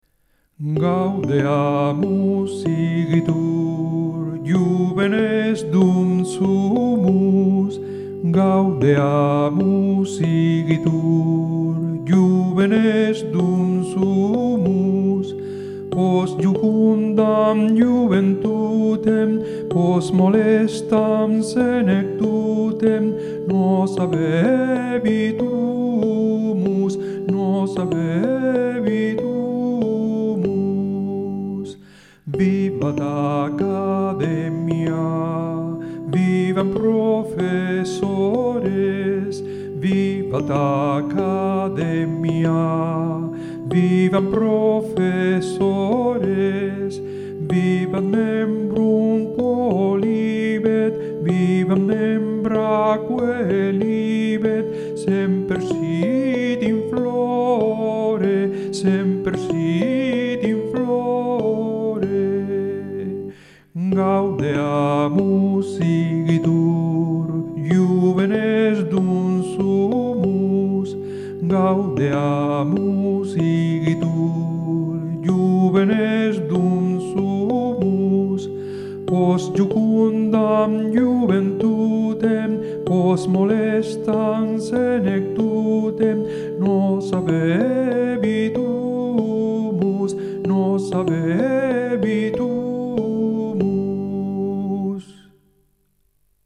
MP3 versión a una voz en Sol mayor
Cantado - MP3